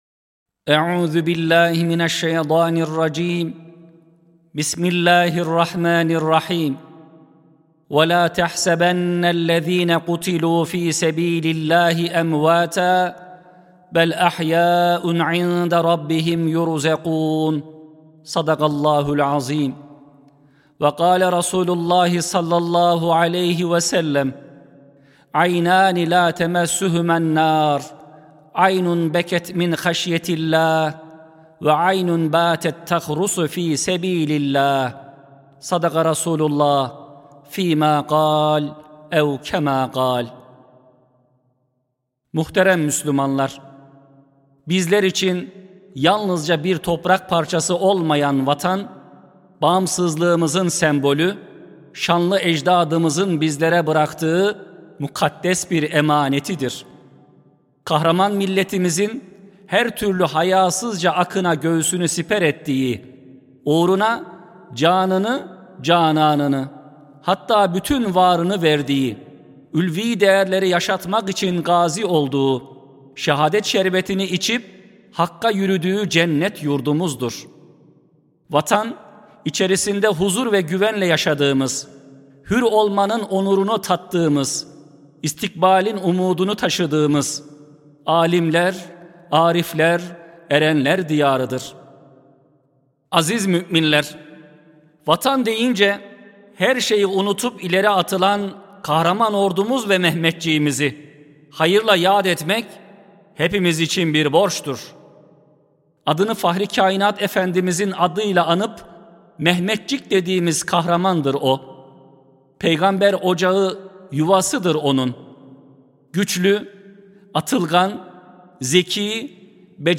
14.11.2025 Cuma Hutbesi: Vatan ve Millet Ruhumuz (Sesli Hutbe, Türkçe, İngilizce, İtalyanca, İspanyolca, Almanca, Fransızca, Rusça, Arapça)
Sesli Hutbe